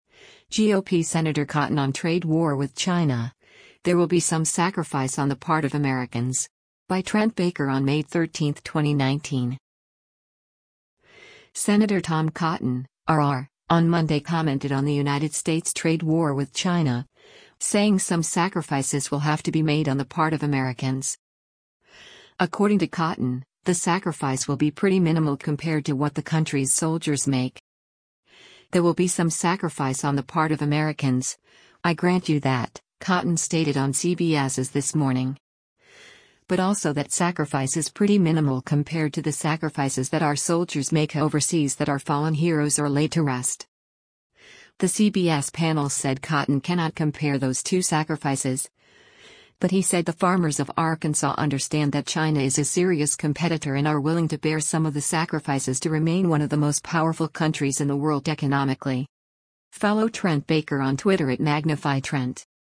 “There will be some sacrifice on the part of Americans, I grant you that,” Cotton stated on CBS’s “This Morning.”
The CBS panel said Cotton cannot compare those two sacrifices, but he said the farmers of Arkansas “understand that China is a serious competitor” and are “willing” to bear some of the sacrifices to remain one of the most powerful countries in the world economically.